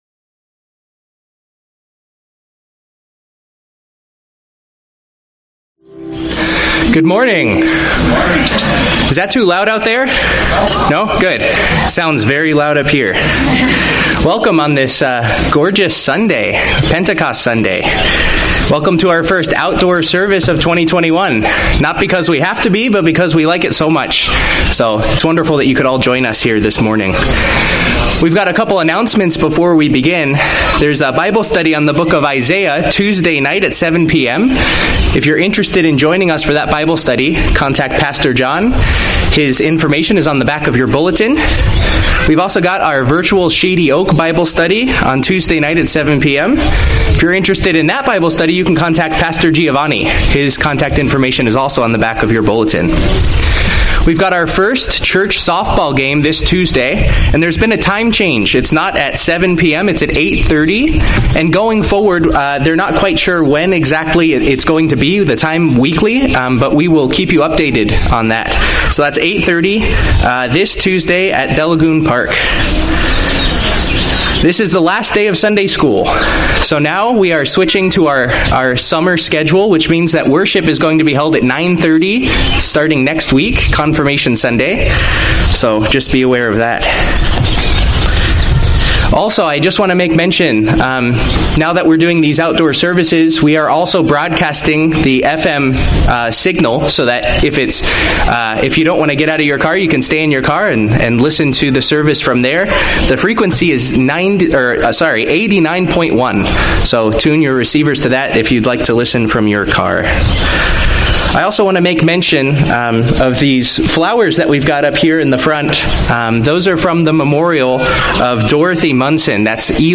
Children's Sermon